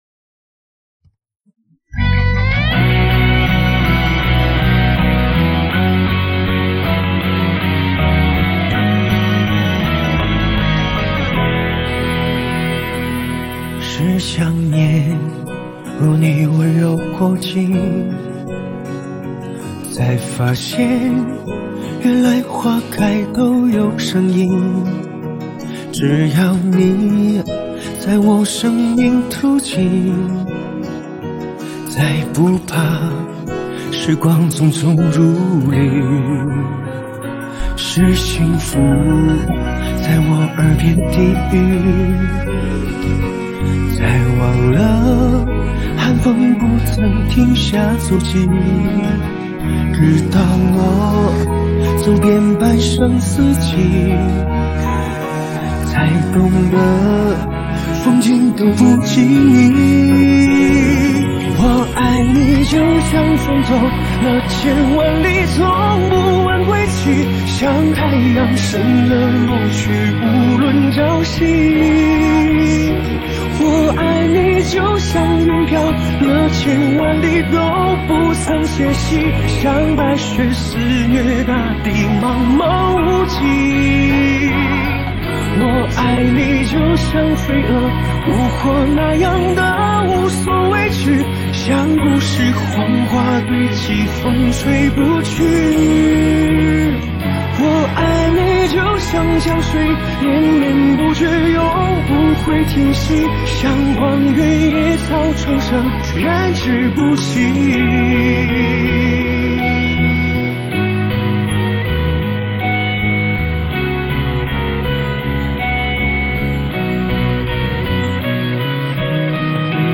华语